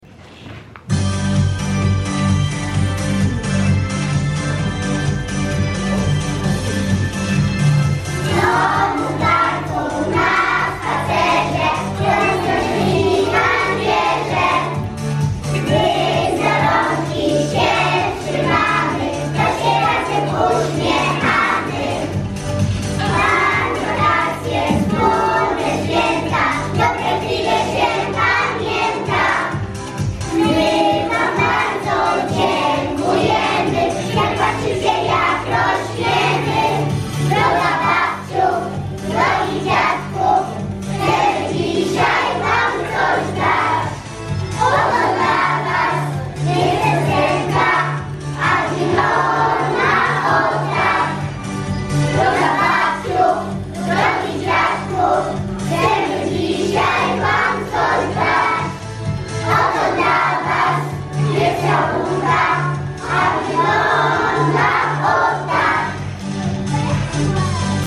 22 stycznia odbyła się w naszej szkole wspaniała uroczystość.
"Droga Babciu, Drogi Dziadku..." w wykonaniu uczniów klas I - III.
droga_babciu_drogi_dziadku_spiewaja_uczniowie_pspk_sty_2024.mp3